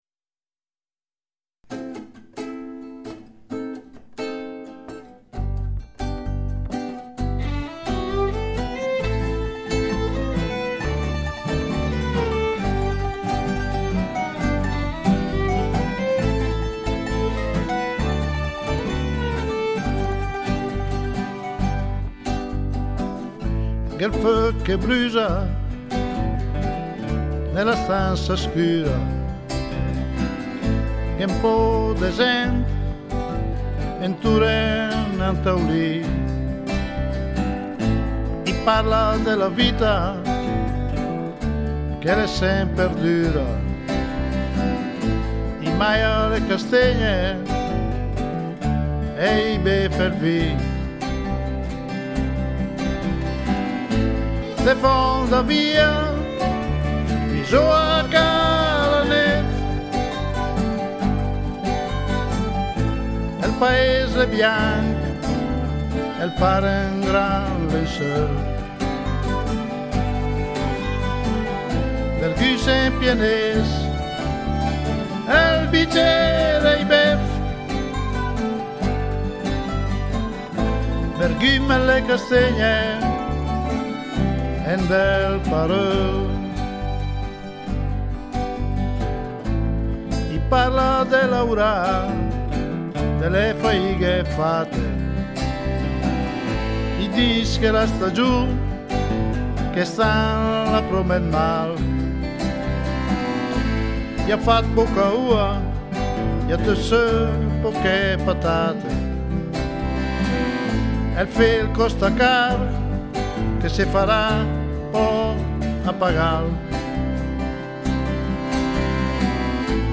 nuovo CD di canzoni in dialetto bresciano